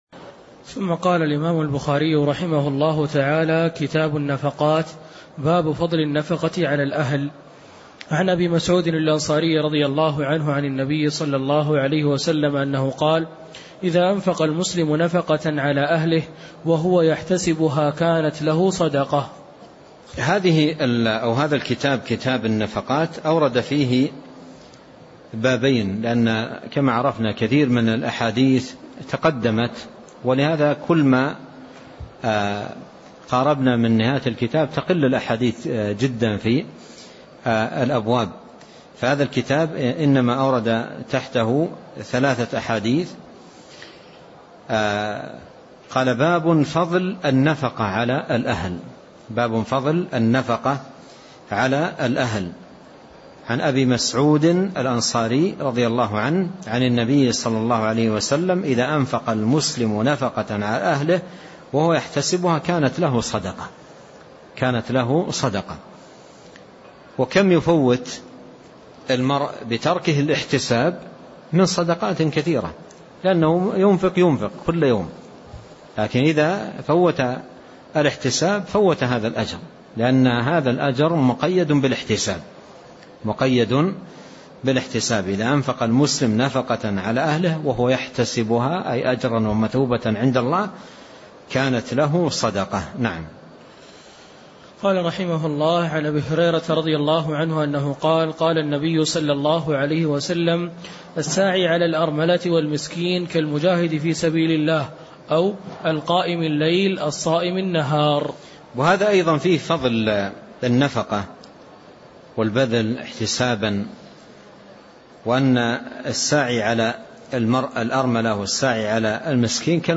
تاريخ النشر ٢٦ رجب ١٤٣٥ هـ المكان: المسجد النبوي الشيخ